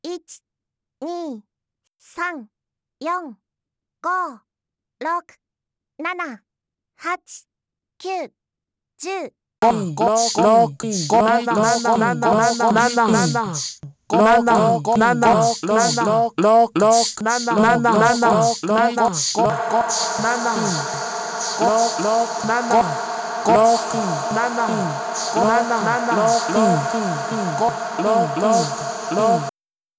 TEMPERAは「やや」ハイファイ寄り。
3回繰り返し最初はそのまま、次にグラニュラー加工、更に内蔵エフェクトを加えたものです。
TEMPERAはジューサー。